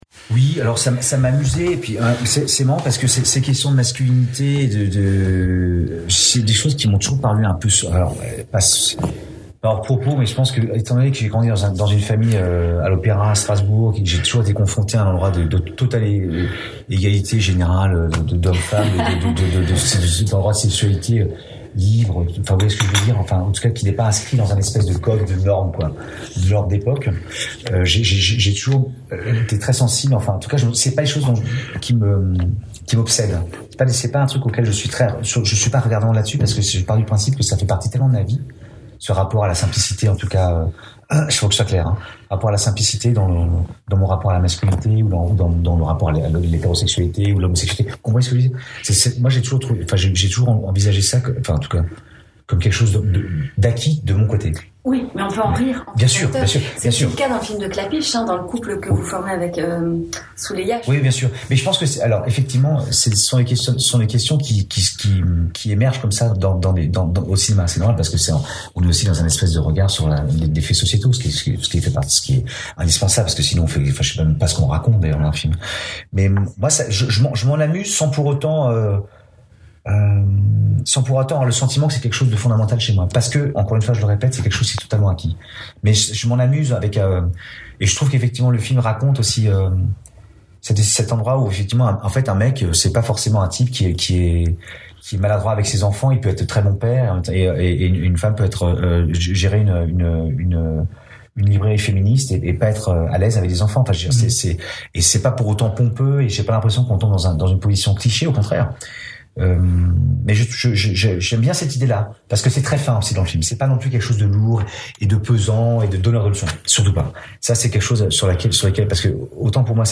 Rencontre.«